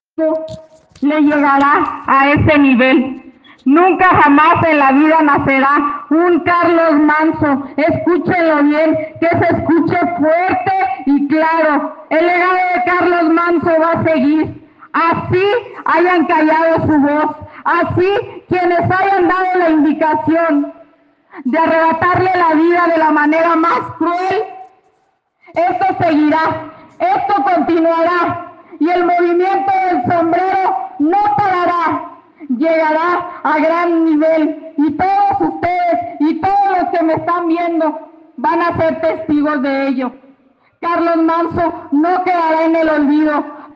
Siendo las 15:40 horas, termina la sesión; Grecia Itzel Quiroz García había rendido protesta y expuso en tribuna su sentir.